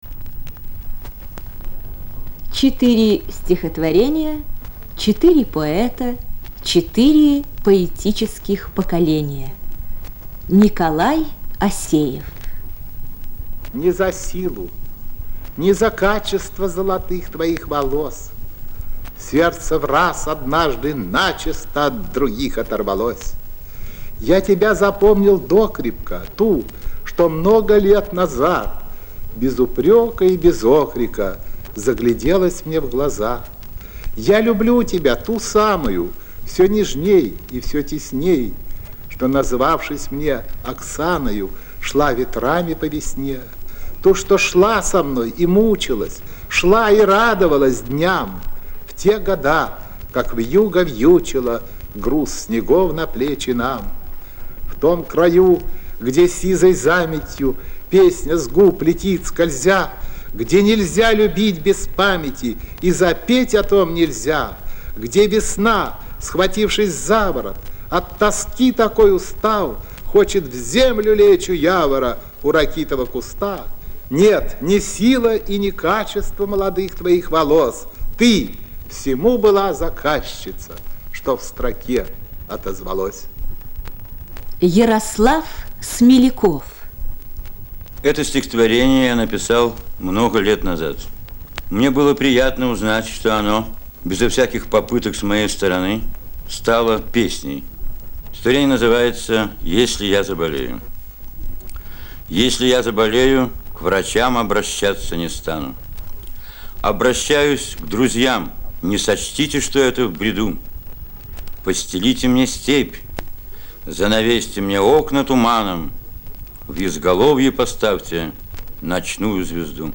На пятой звуковой странице вы услышите любимые стихи четырёх поэтов - Николая Асеева, Ярослава Смелякова, Юрия Левитанского, Беллы Ахмадулиной.
Звуковая страница 5 - Любимые стихи поэтов Н.Асеева, Я.Смелякова, Ю.Левитанского, Б.Ахмадулиной.